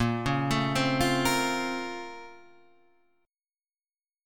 A# Minor Major 9th